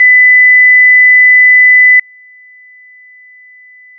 250Hzの音データ/基準音と低減音 [いびきや大型犬の鳴き声など] 500Hzの音データ/基準音と低減音 [会話音[男性の声]など] 1000Hzの音データ/基準音と低減音 [会話音[女性の声]など]